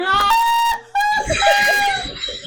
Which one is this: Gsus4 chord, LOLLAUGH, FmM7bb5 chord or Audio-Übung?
LOLLAUGH